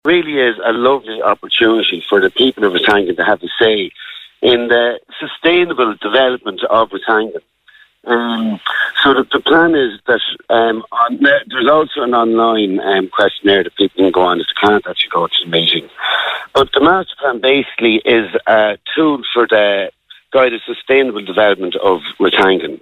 Cllr Brian O'Loughlin explains why it's important for locals to get involved.